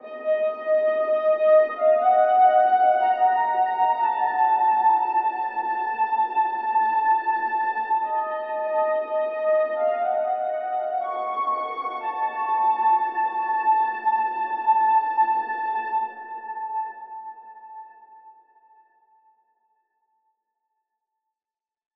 AV_Sneaky_Strings_60bpm_C#min
AV_Sneaky_Strings_60bpm_Cmin.wav